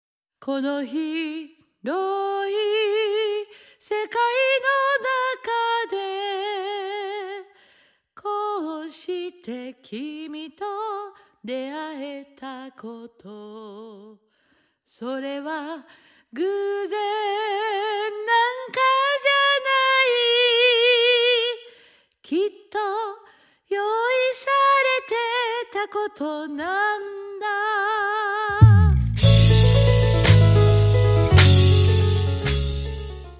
新潟長岡アマテラススタジオにて収録